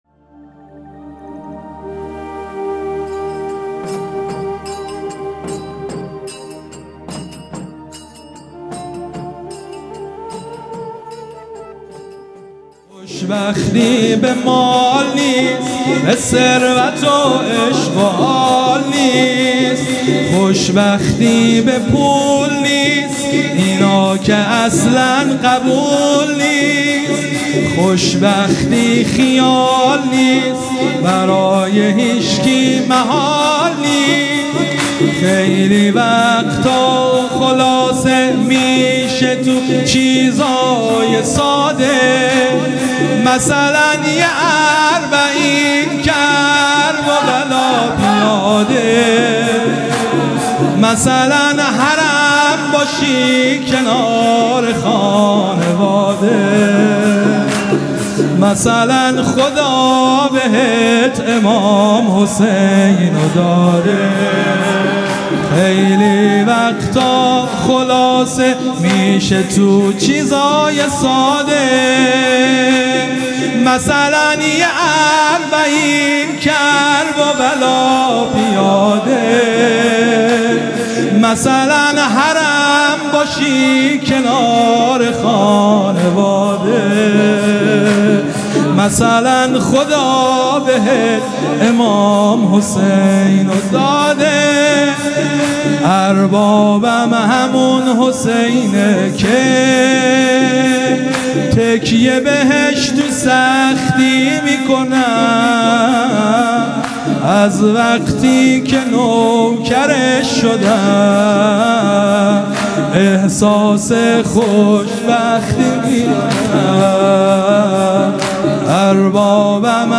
شور | مثلا یه اربعین کربلا پیاده
مداحی شور
دهه اول - شب سوم محرم 1402